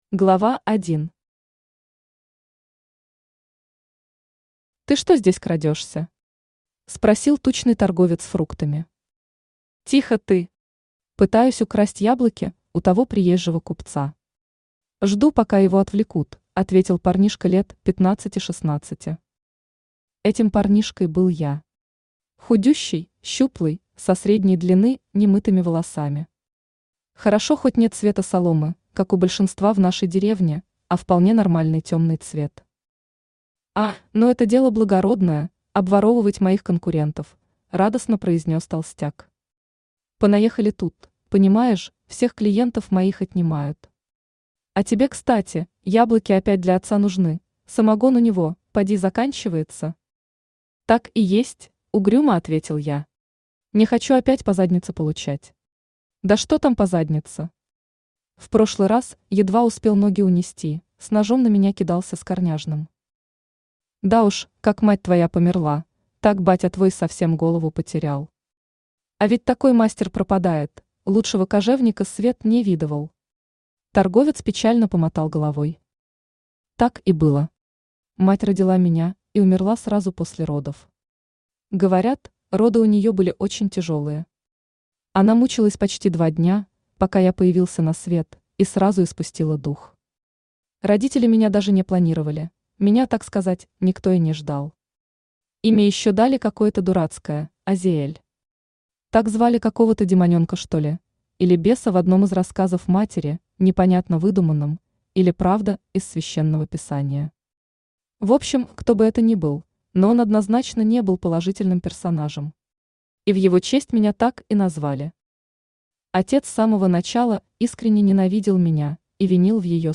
Аудиокнига Азиэль | Библиотека аудиокниг
Aудиокнига Азиэль Автор Михаил Кононов Читает аудиокнигу Авточтец ЛитРес.